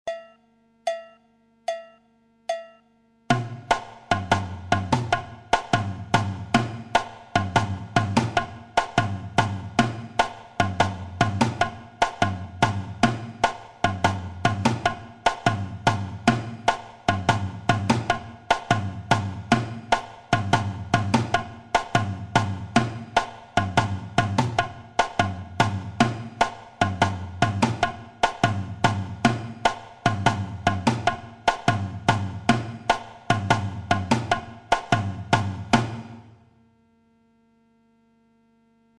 Section rythmique phrasé bossa nova
Figure phrasé bossa avec tambourim, surdo et clave.batida de base, Téléchargez ou écoutez dans le player.
Phrasé bossa nova à la guitare